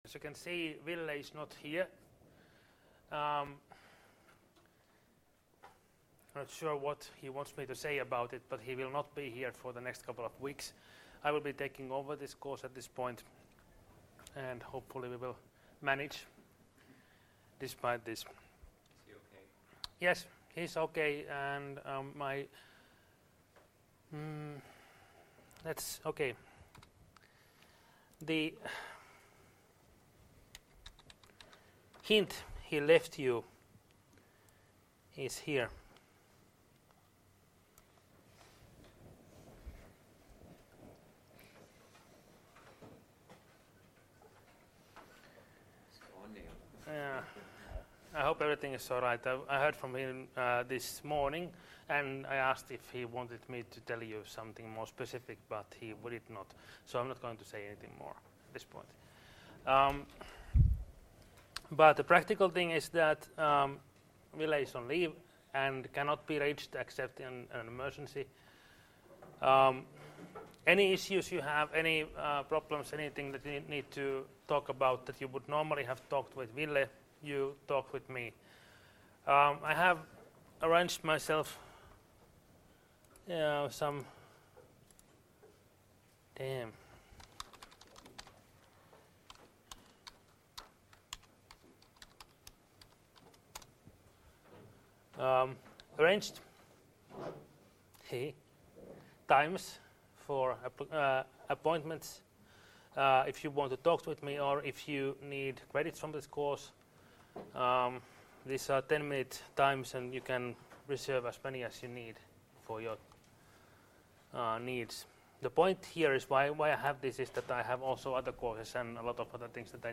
Luento 6.3.2017 — Moniviestin